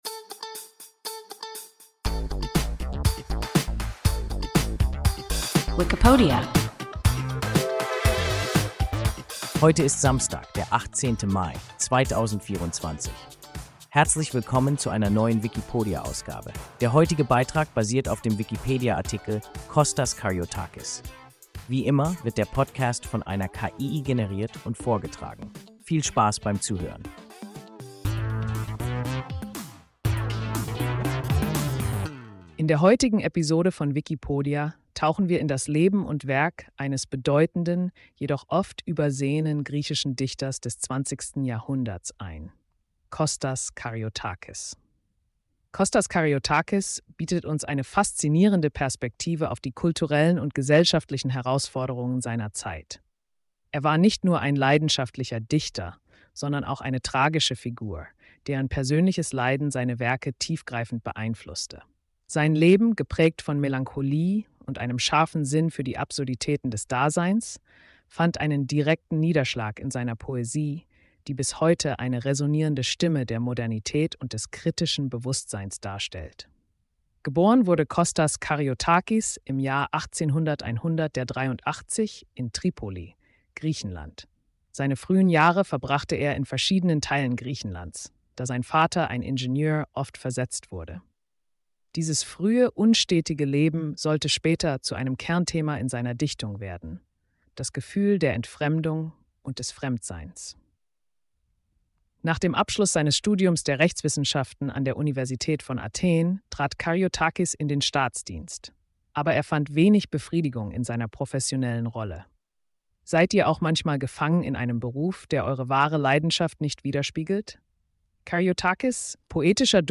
Kostas Karyotakis – WIKIPODIA – ein KI Podcast